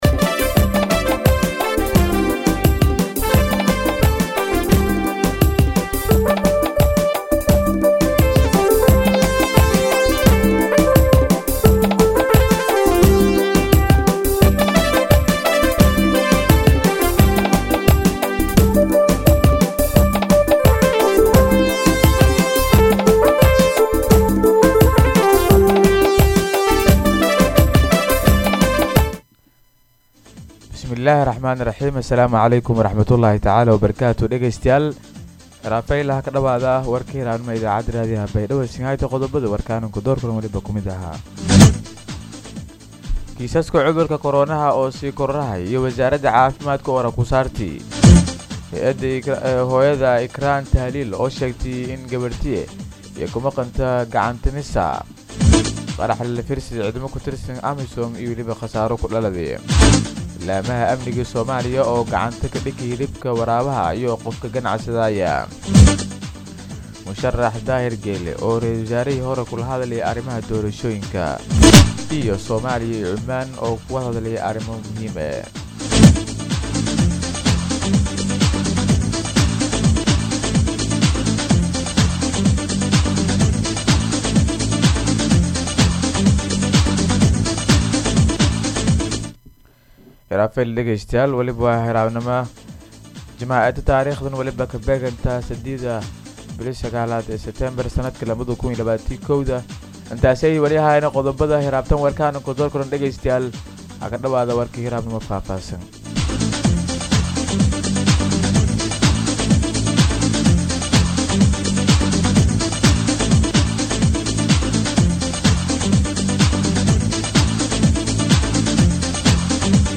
DHAGEYSO:-Warka Subaxnimo Radio Baidoa 3-9-2021